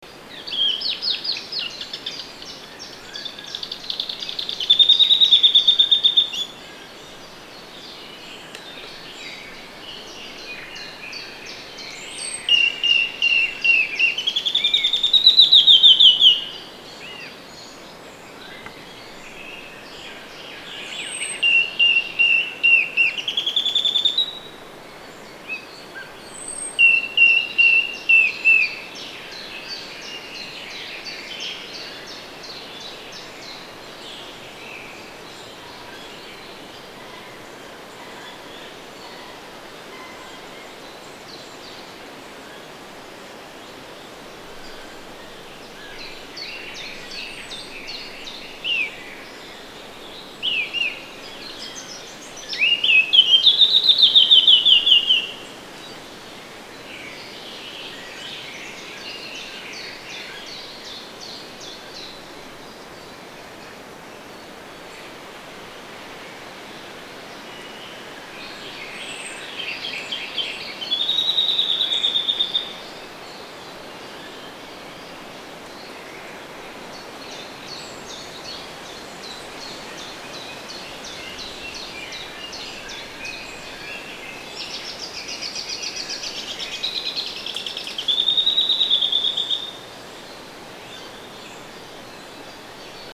So we went out to make some sound recordings for documentation purposes.
070624, Eurasian Blackcap Sylvia atricapilla, atypical song, Torgau, Germany
sylvia-atricapilla_atypical-song.mp3